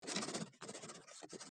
Download Writing sound effect for free.
Writing